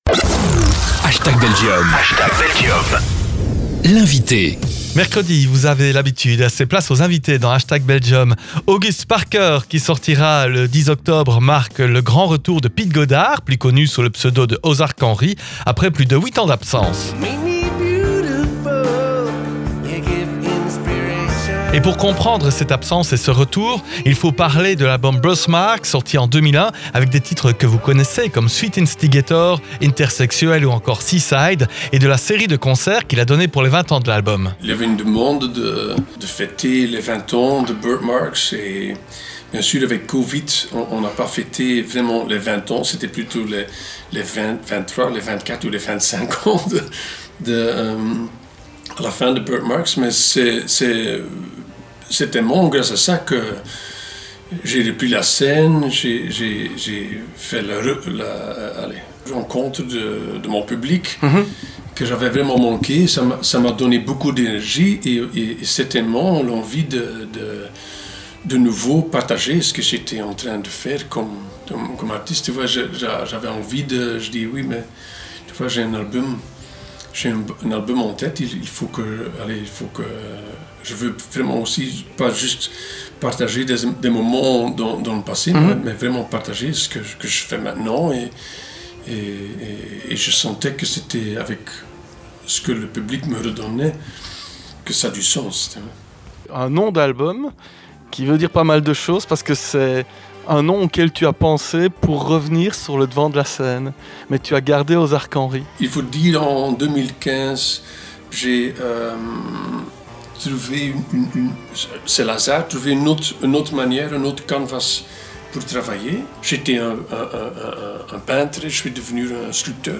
On a eu l'immense honneur de recevoir Piet Goddaer alias Ozark Henry, qui est de retour avec un nouvel album, 8 ans après la derniere sortie !"August Parker" sortira le 10 octobre, une interview captivante à découvrir...